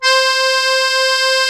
MUSETTE 1.10.wav